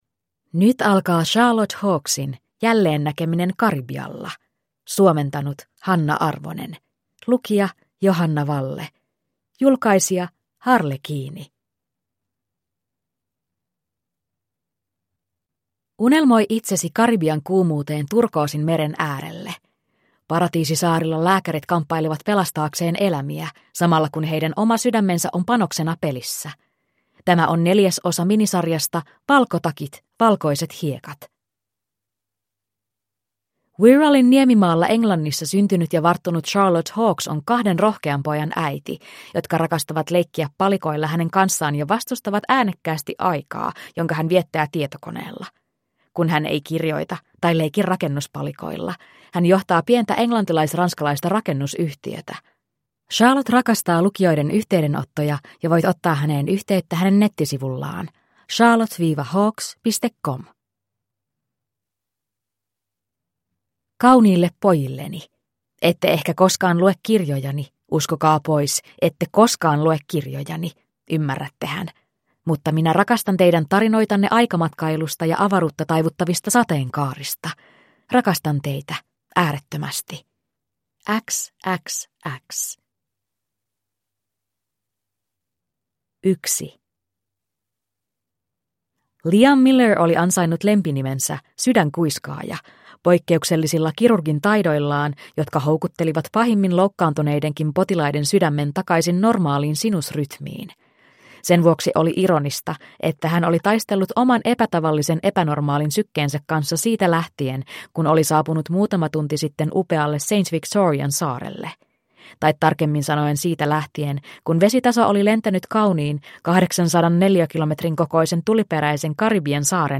Jälleennäkeminen Karibialla (ljudbok) av Charlotte Hawkes